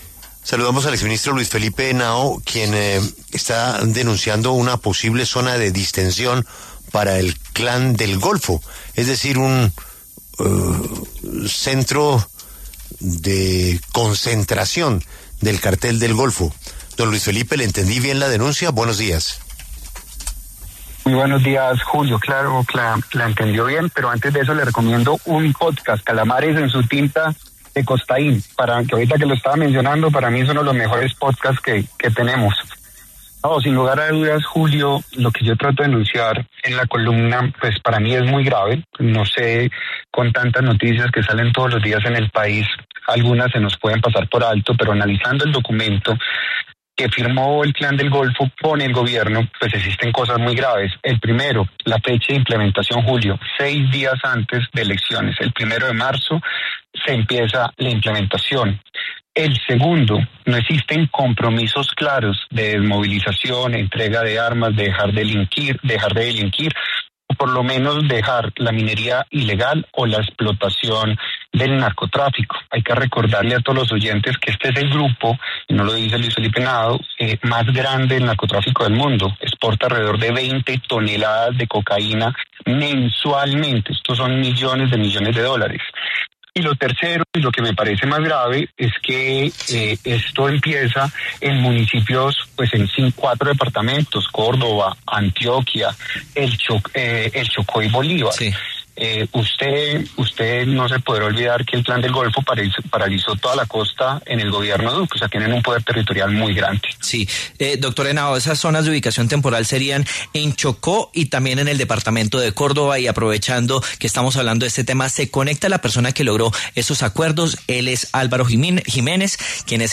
El exministro de Vivienda, Luis Felipe Henao, pasó por los micrófonos de La W, con Julio Sánchez Cristo, para denunciar una posible zona de distención para el Clan del Golfo, advirtiendo tres preocupaciones en medio de la época electoral.